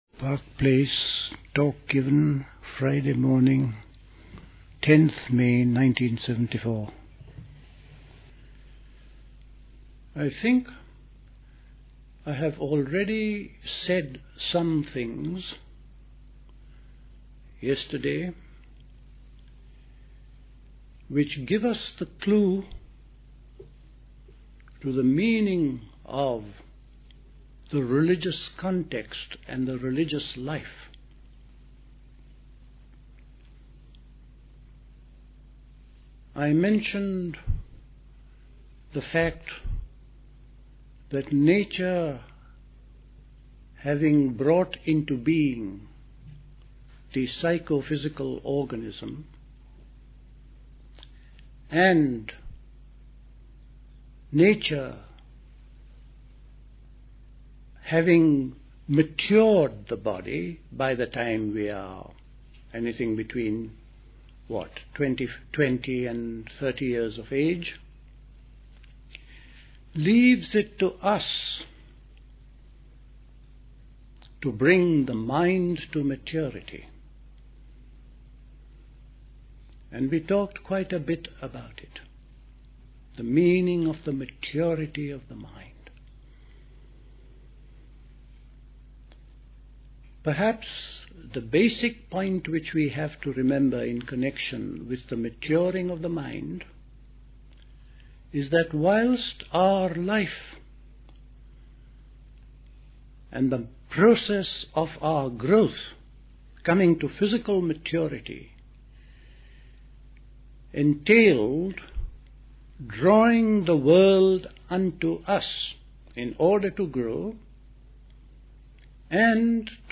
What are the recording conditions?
Recorded at the 1974 Park Place Summer School.